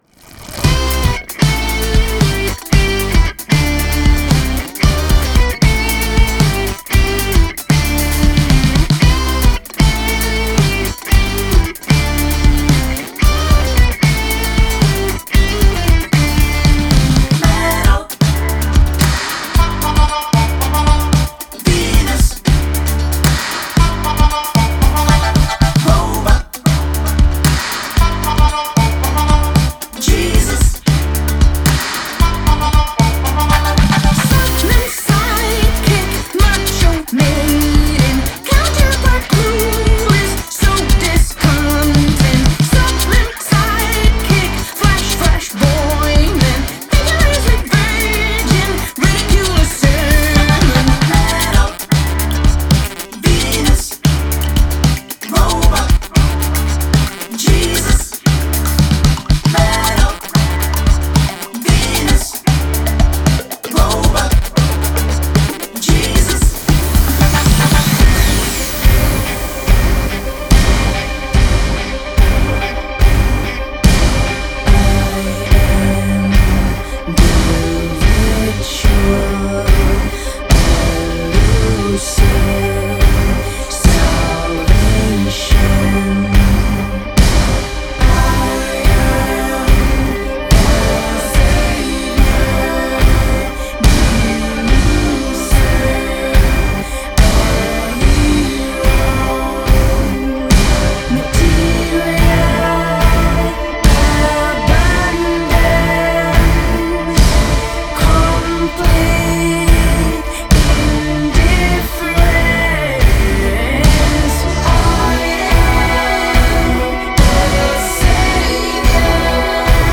Genre: Indie Pop, Female Vocal, Experimental